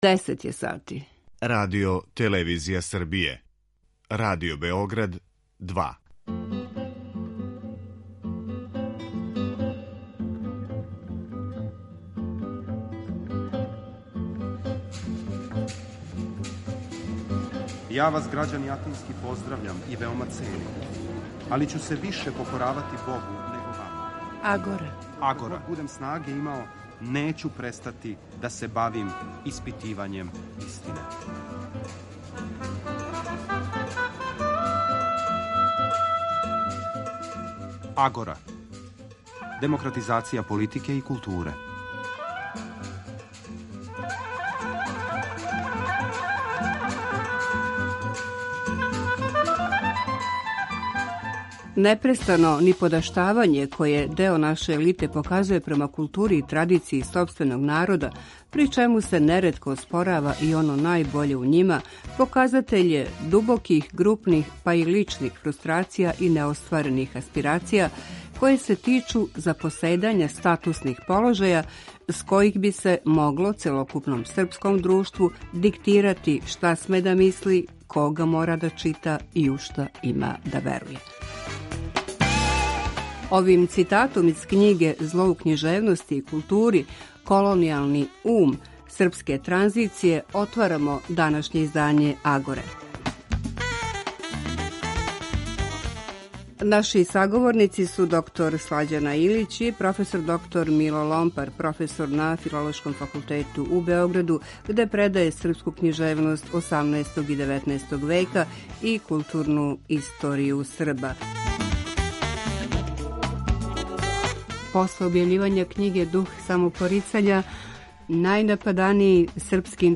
Радио-магазин